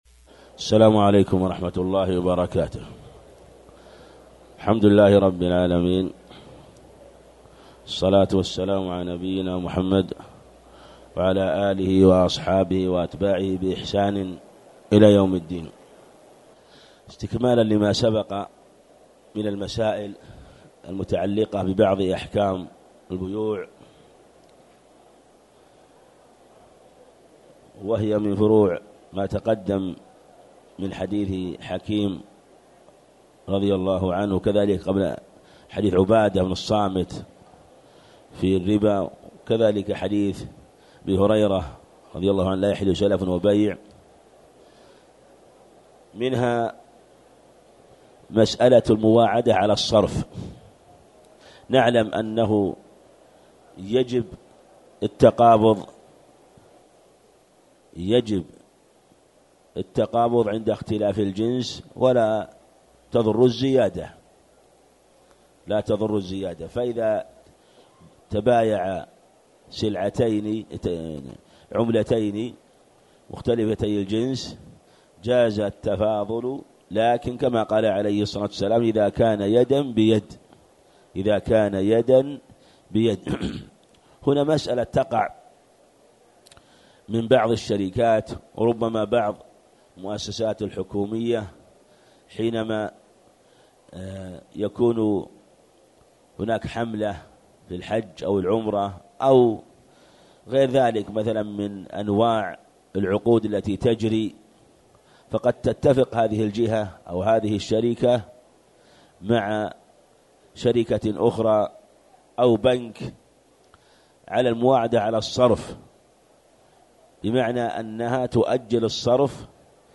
تاريخ النشر ١٧ رمضان ١٤٣٨ هـ المكان: المسجد الحرام الشيخ